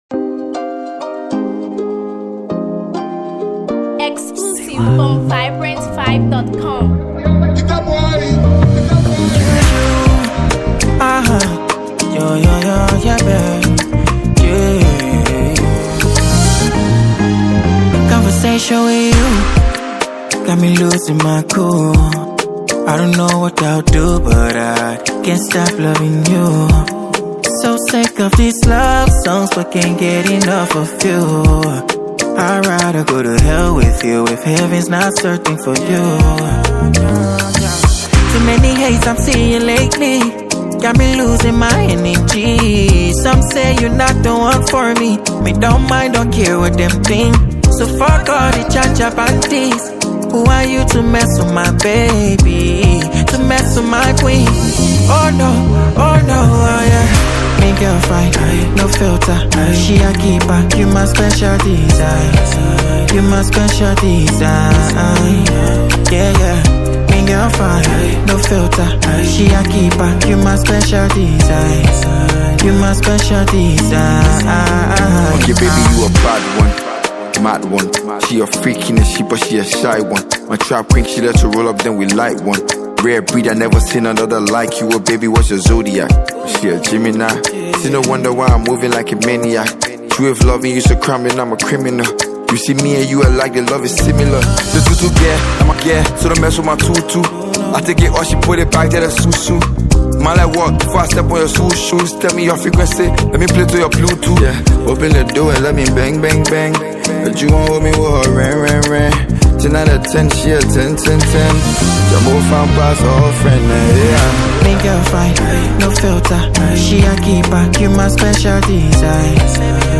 It’s raw, romantic, and real.
the perfect blend of Afrobeat, R&B, and Hip-Hop